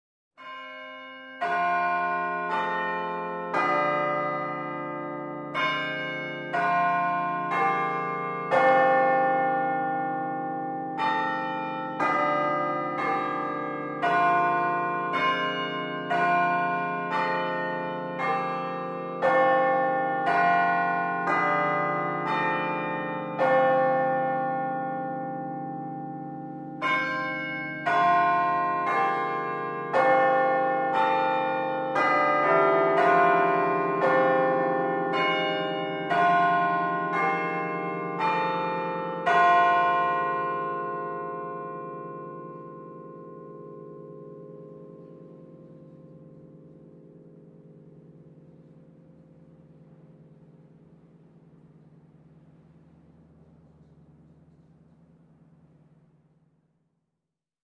Bell Ringing for healing Prayer (256 kbps)
• Category: Church bells 1154
On this page you can listen to audio bell ringing for healing prayer (256 kbps).